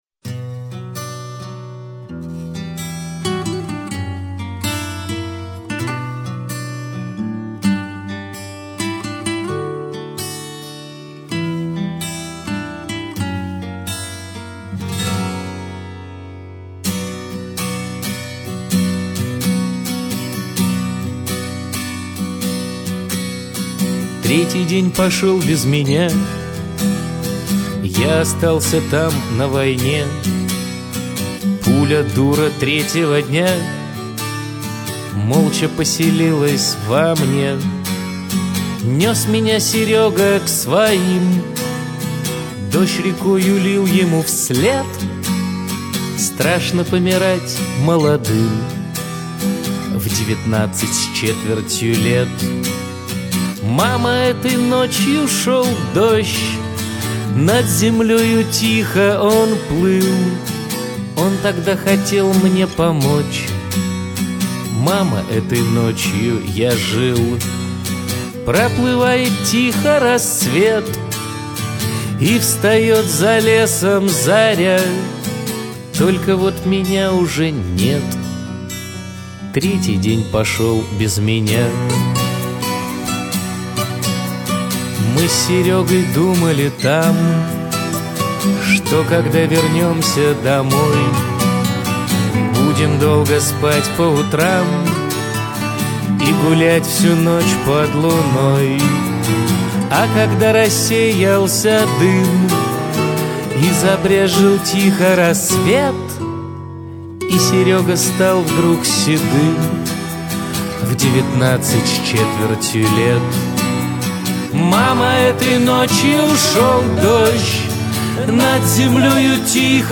классический рок, глэм-рок, русский шансон, рок-н -ролл.
Лидер-вокал